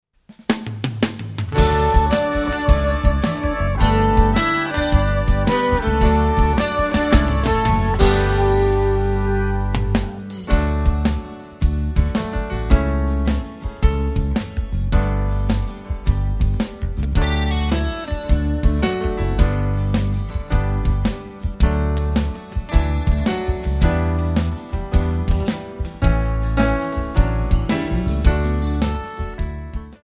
Backing track Karaoke